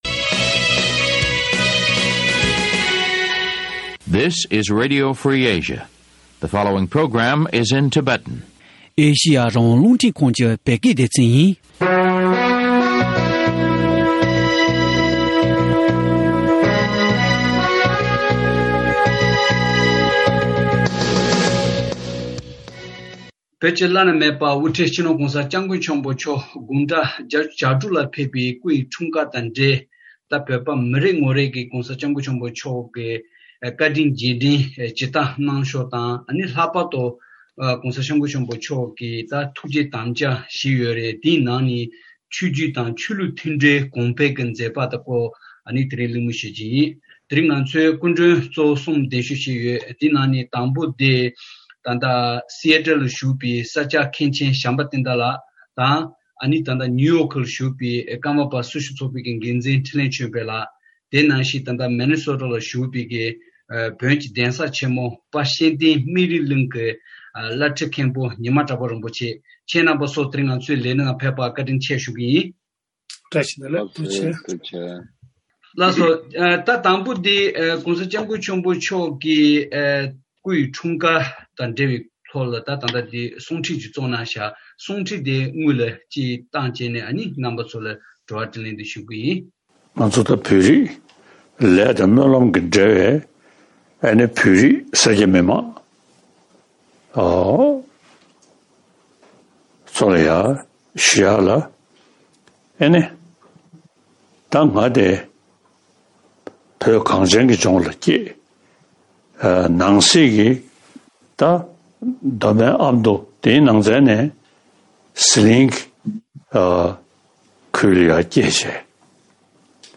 ༧གོང་ས་མཆོག་ལ་བཀའ་དྲིན་རྗེས་དྲན་ཇི་ལྟར་ཞུ་ཕྱོགས་དང་ཐུགས་བསྐྱེད་དམ་བཅའ་སྐོར་གླེང་མོལ།